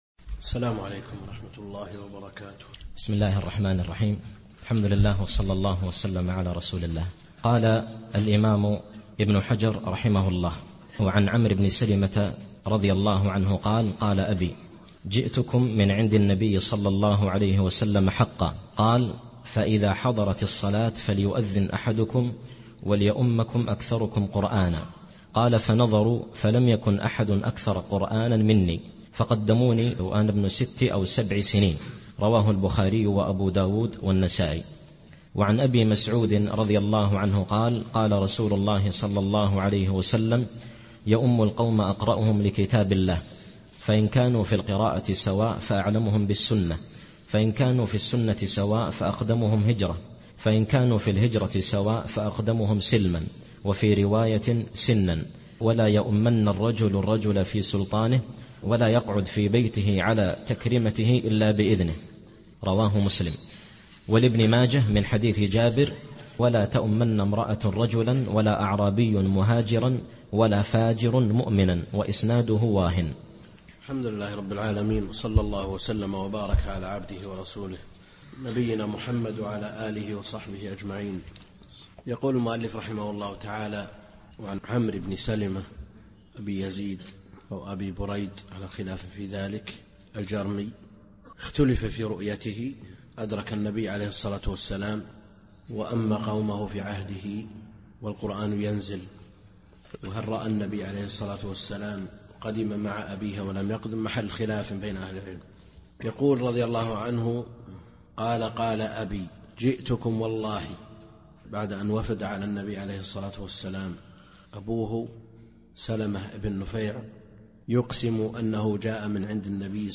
الدرس السابع و العشرون من دروس شرح بلوغ المرام كتاب الصلاة للشيخ عبد الكريم الخضير